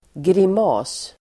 Ladda ner uttalet
Uttal: [grim'a:s]